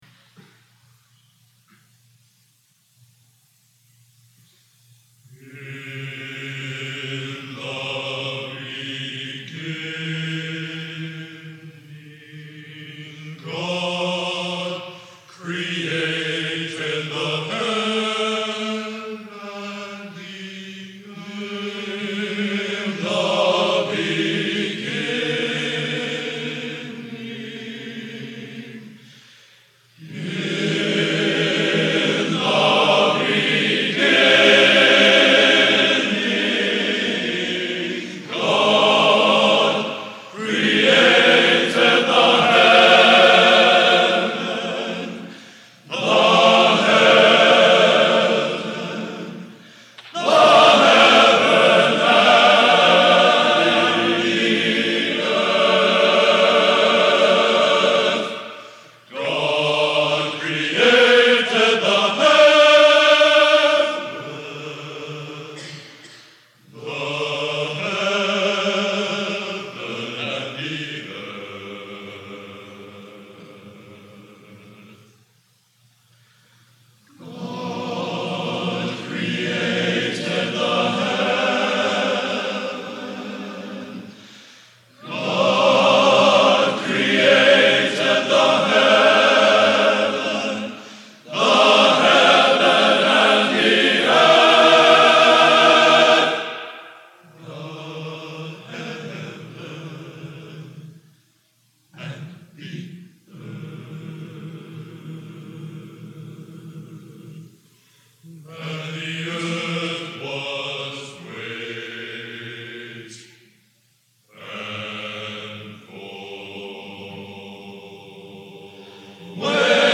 Genre: A Cappella Classical Sacred | Type: